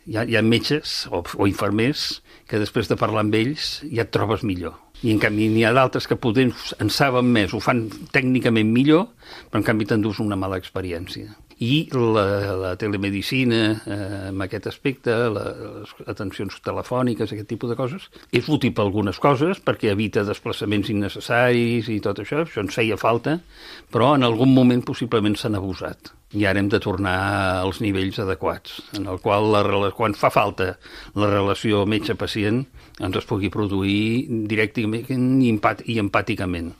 L’HORA DE LA GENT GRAN ha entrevistat el calellenc Joan Guix, qui va ser secretari de Salut Pública de la Generalitat de Catalunya durant la primera onada de la pandèmia de la Covid, que ha recordat com un moment d’extrema duresa en què l’equip polític i tècnic que estava al capdavant de la gestió de la crisi al país va sentir molta impotència.